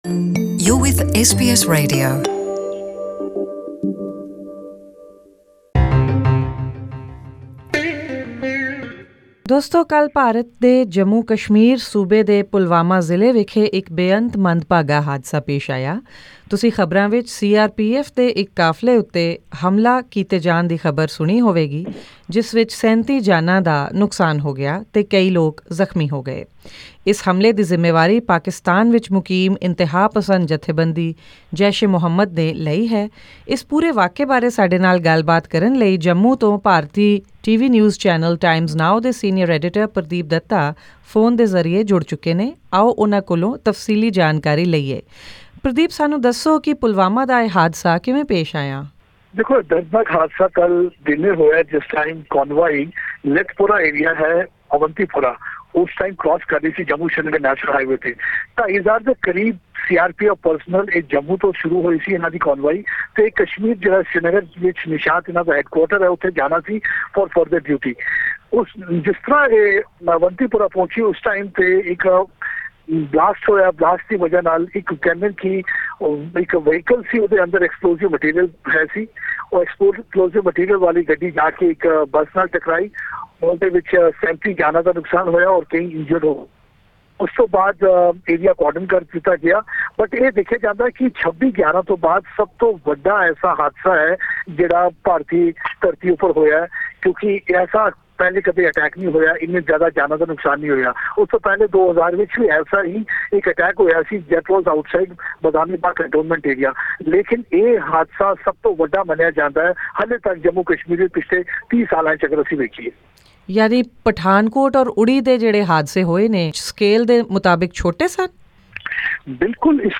SBS Punjabi spoke with the Jammu-based